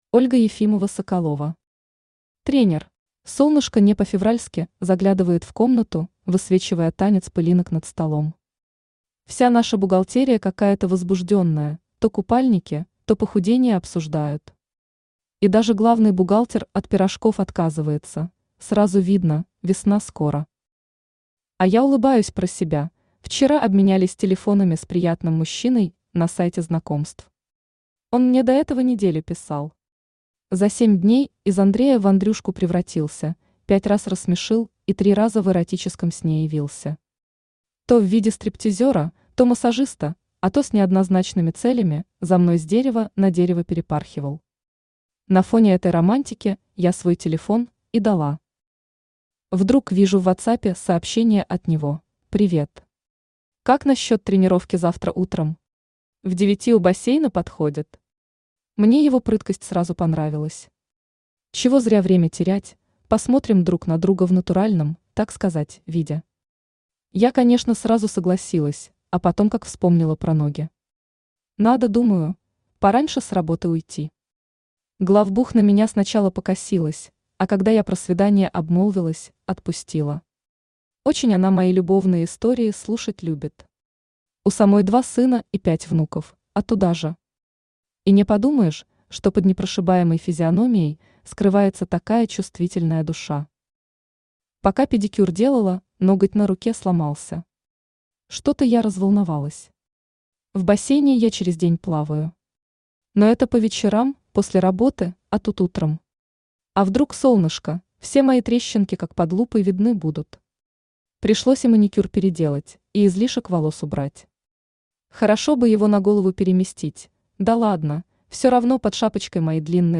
Аудиокнига Тренер | Библиотека аудиокниг
Aудиокнига Тренер Автор Ольга Ефимова-Соколова Читает аудиокнигу Авточтец ЛитРес.